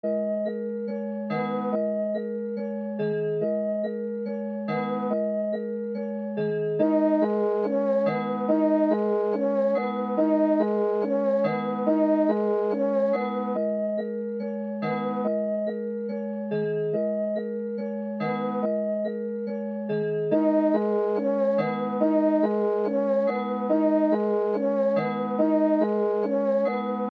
no_call_71bpm_oz.mp3